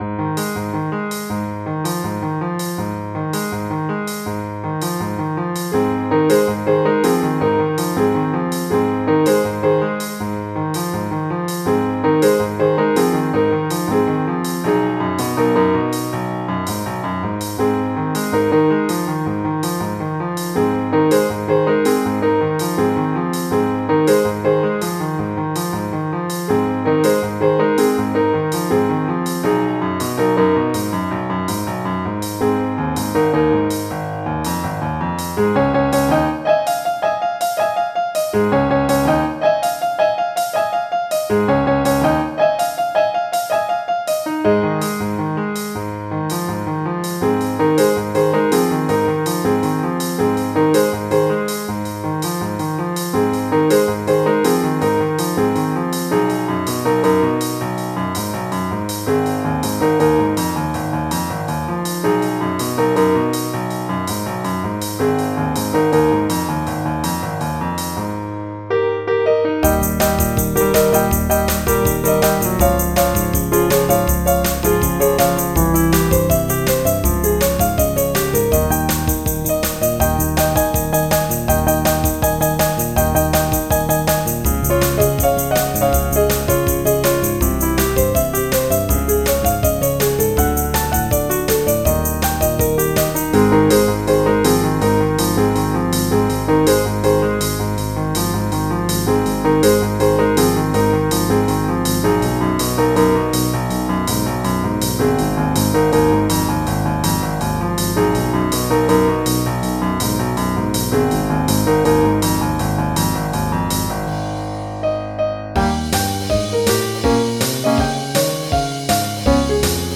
Jazz
MIDI Music File
General MIDI